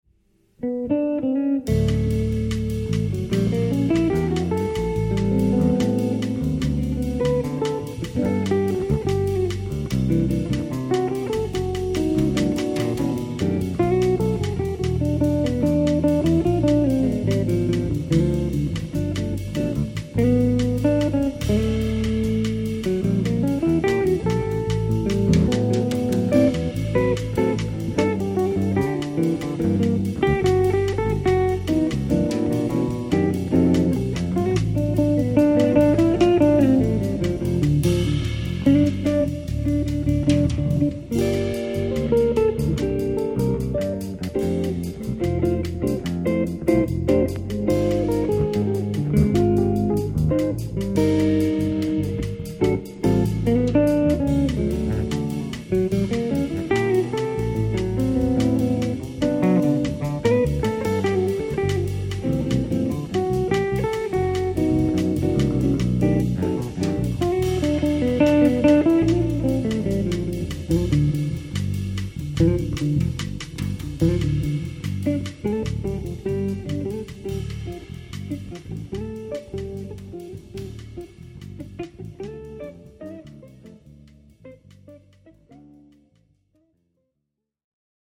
Bossa Nova